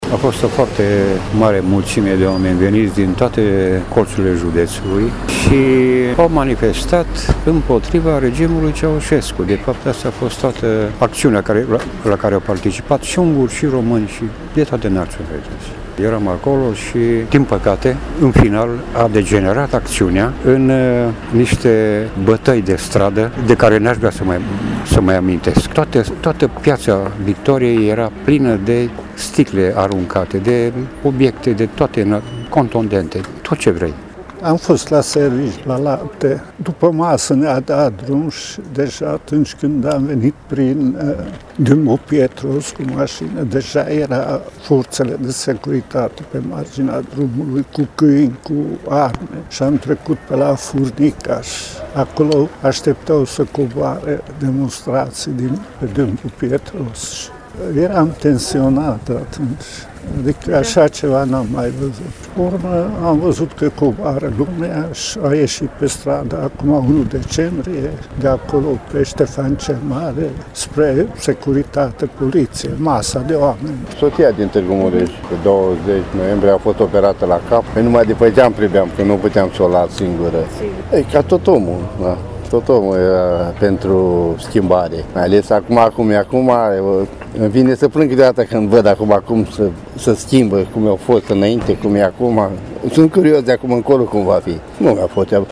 Tîrgumureşenii povestesc cu reţinere despre acele momente pe care şi le-ar dori uitate şi speră în continuare că sacrificiul nu a fost în zadar.